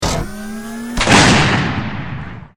battlesuit_pulsegun.ogg